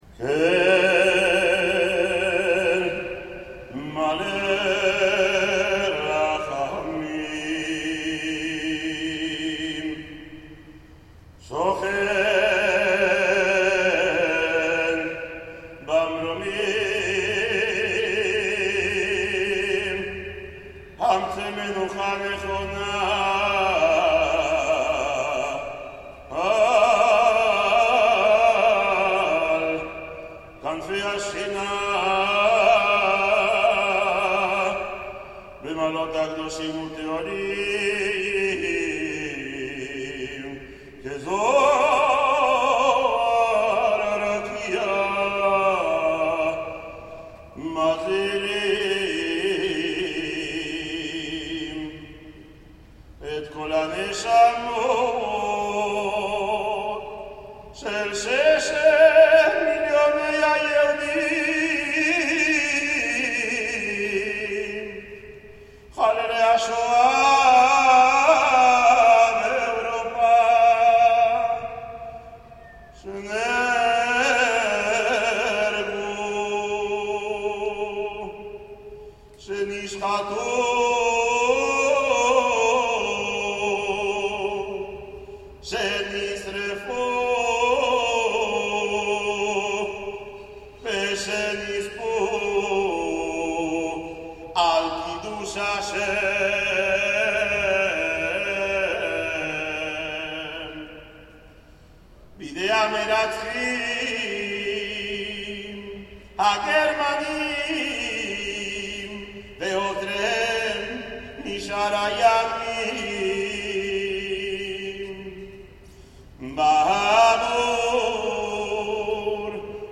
SdR: Actos, aniversarios, manifestaciones, marchas. Registros 01
Manifestación de Taxistas
Av. Pellegrini 2000